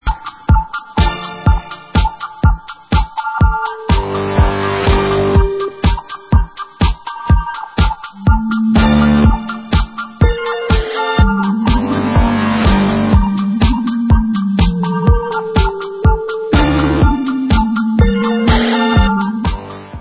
sledovat novinky v oddělení Experimentální hudba